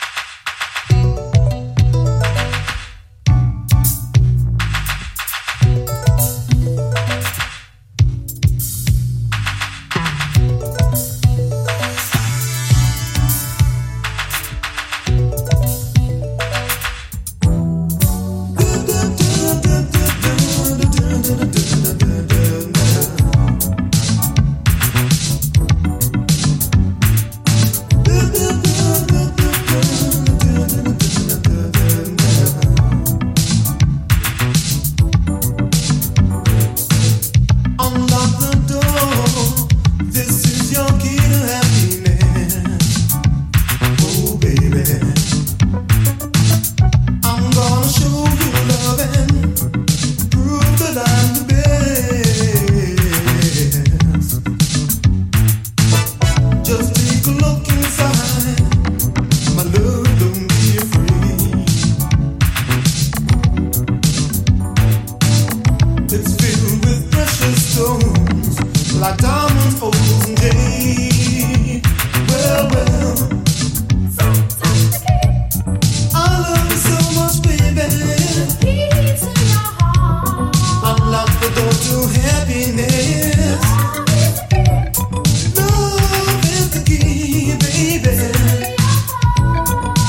Funk / soul
Uk street soul